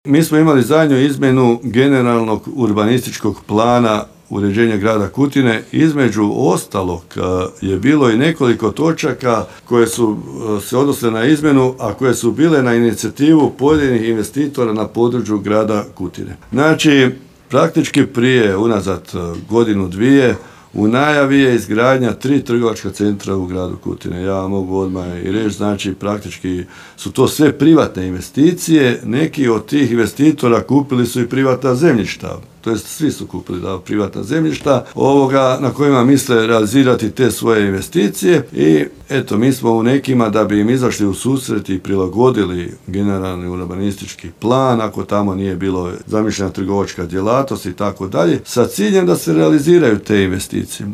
Mnoge građane Kutine zanima zašto prostor bivše stolarije na kojem je najavljen kružni tok i trgovački centar stoji zapušten, potvrdio je to gradonačelnik Kutine Zlatko Babić i pojasnio